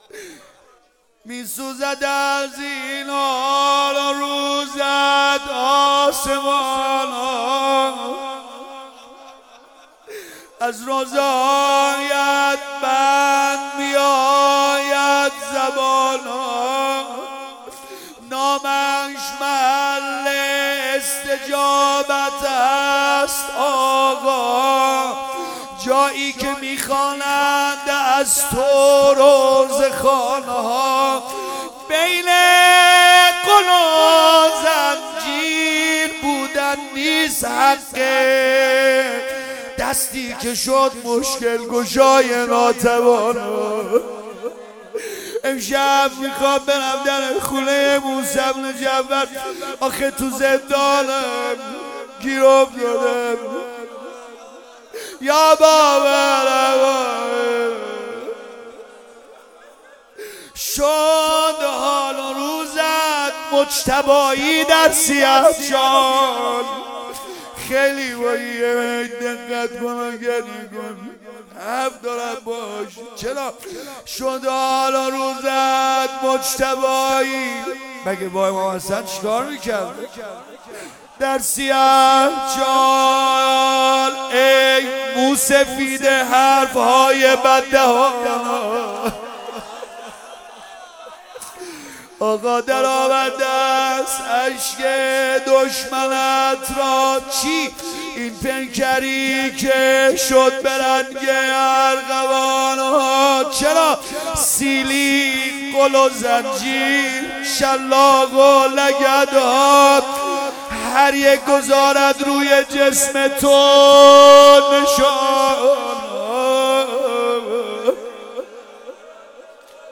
روضه وتوسل به باب الحوائج موسی بن جعفر(ع)، سیدرضا نریمانی -(میسوزد از این حالُ روزت آسمانها...)
مداح سید-رضا-نریمانی